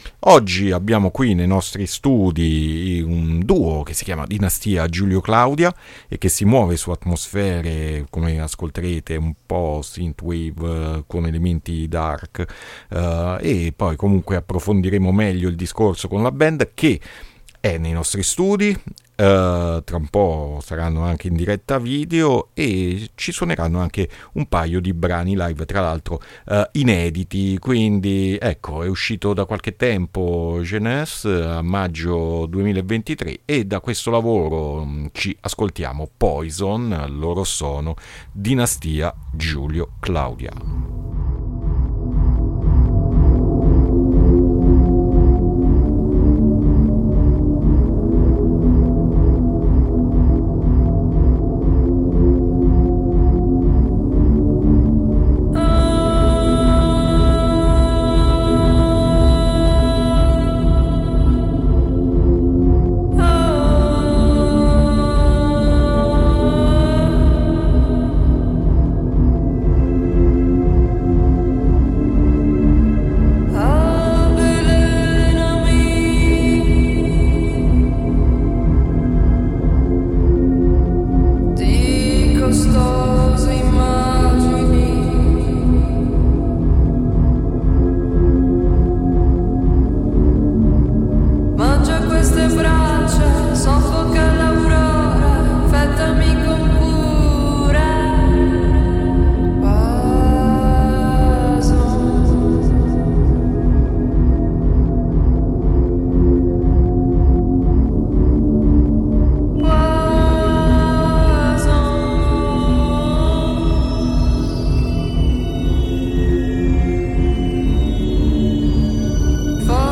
Il duo
anche con set live